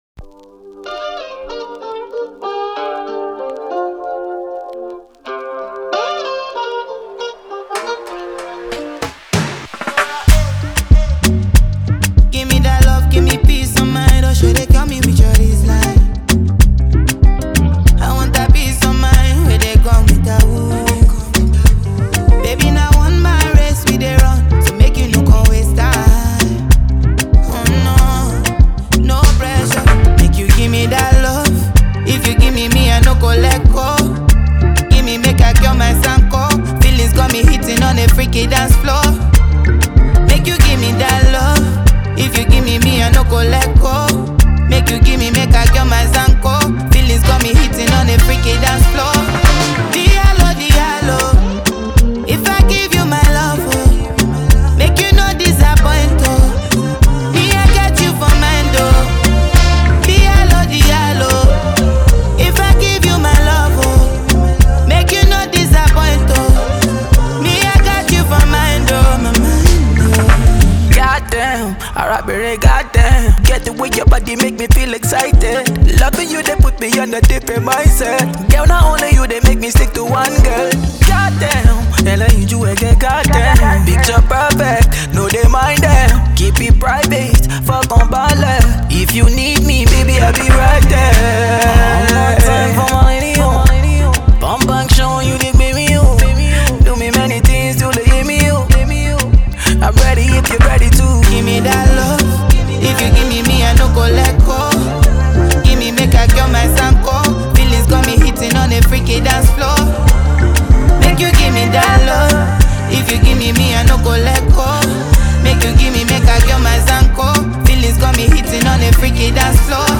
• Жанр: Pop, Hip-Hop